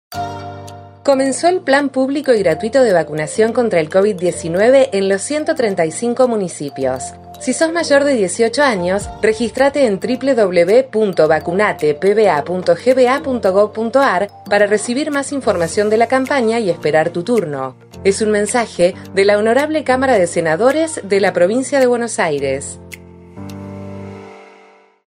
Boletín Radiofónico Registrate para recibir la vacuna